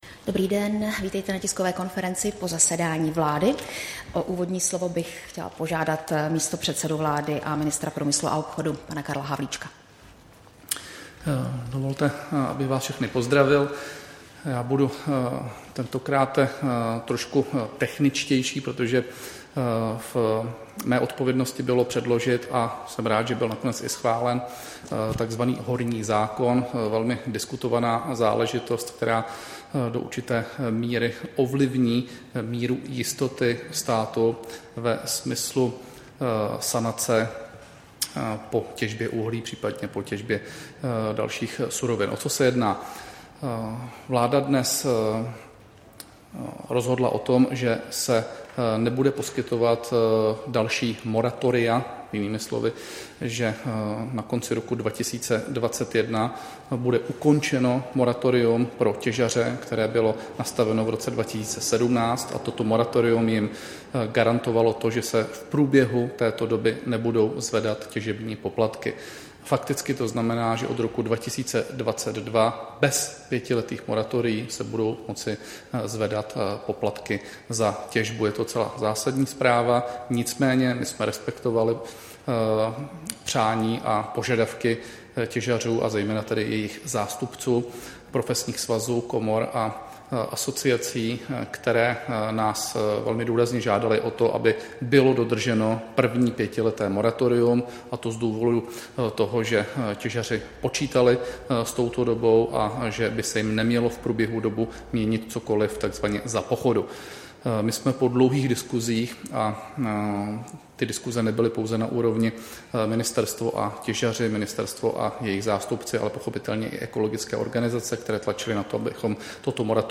Tisková konference po jednání vlády, 17. června 2019